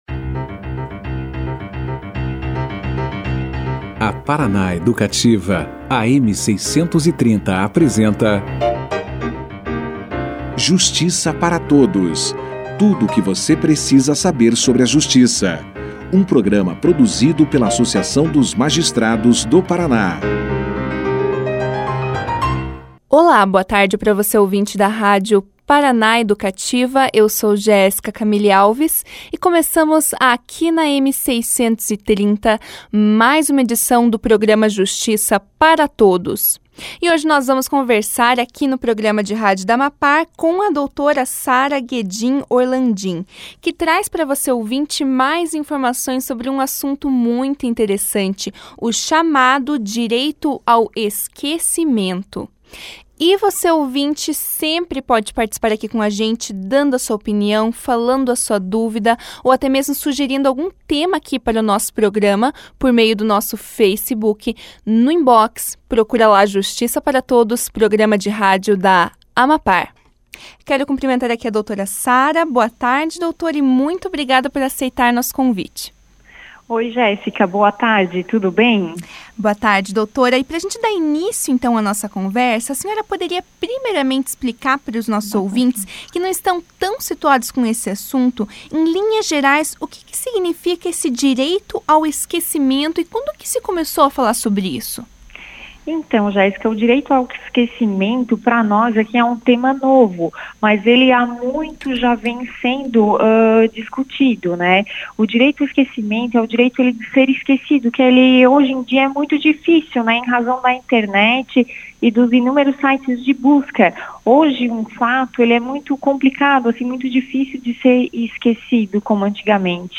Direito ao esquecimento foi o tema debatido no programa de rádio da AMAPAR, Justiça para Todos, na terça-feira (30).